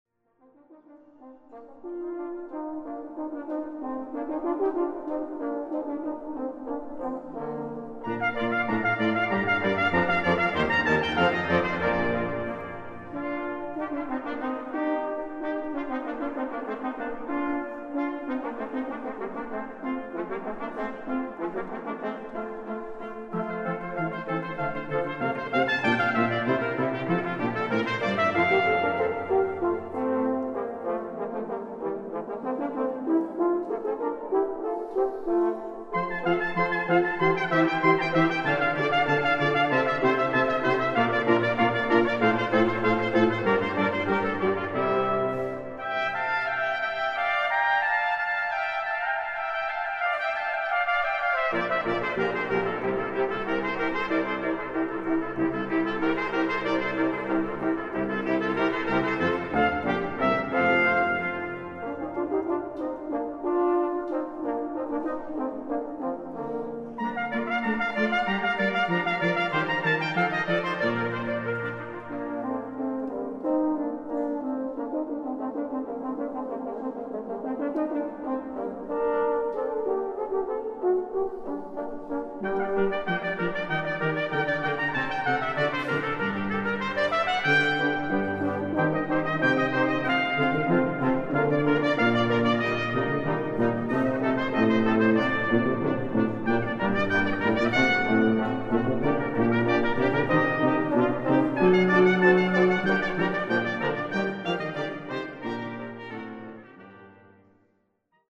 Amadeus Brass Quintett: